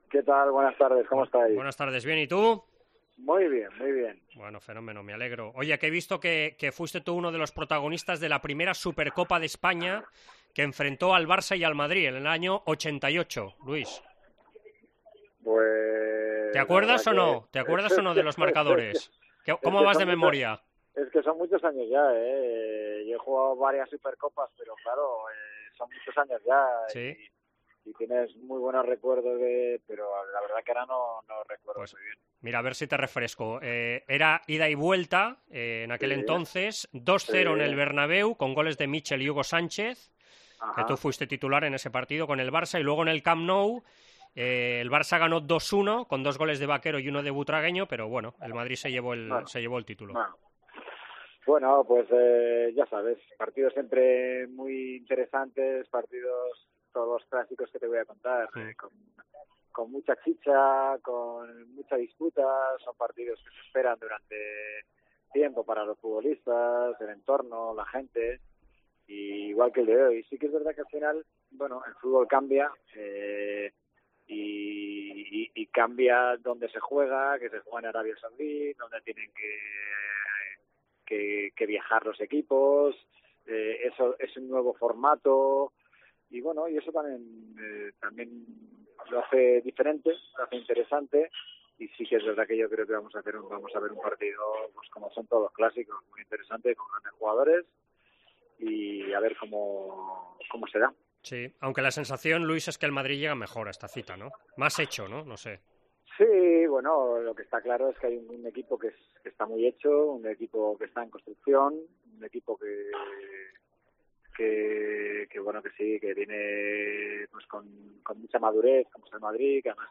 El exjugador de Barça y Madrid Luis Milla analiza en Esports COPE el Clásico que disputan esta noche ambos equipos en Riad (20:00 horas) correspondiente a la primera semifinal de la Supercopa de España.